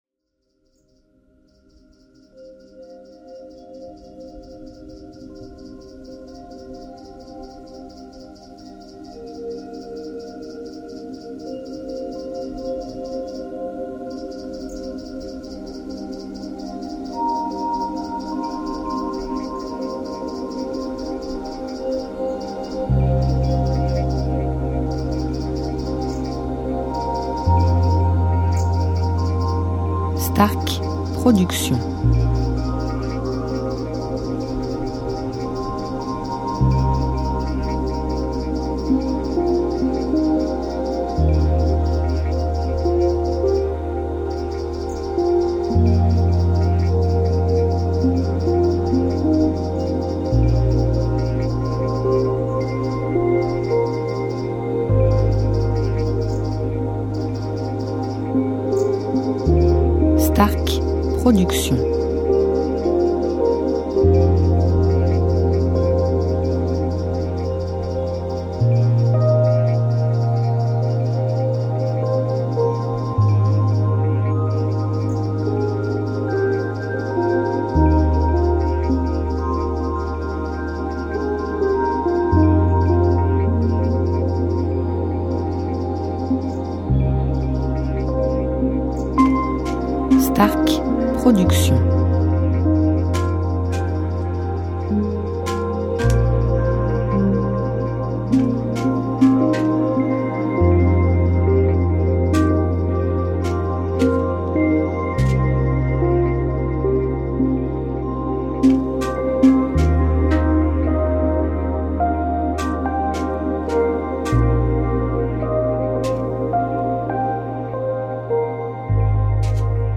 style NewAge Worldmusic durée 1 heure